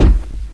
/hl2/sound/npc/antlion_guard/far/
foot_light1.ogg